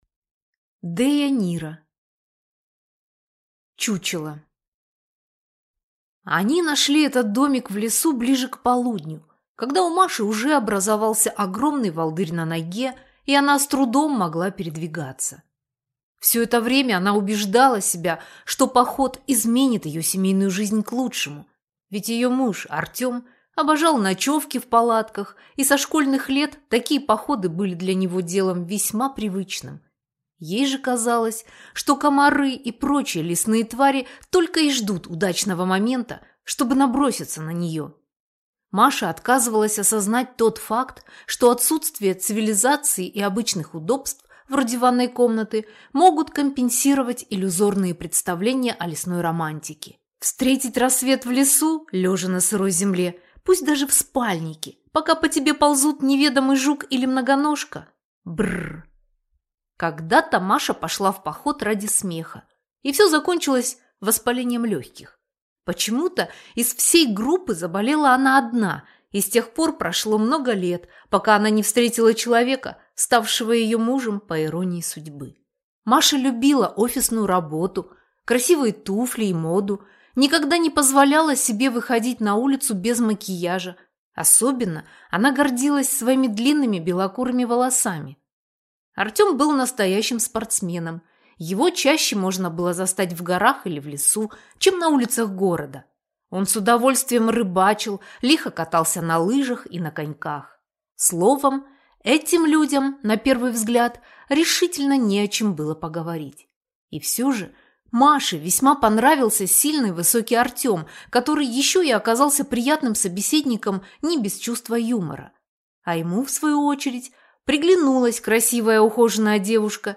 Аудиокнига Чучело | Библиотека аудиокниг